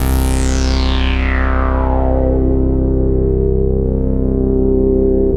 SYN JD-8000L.wav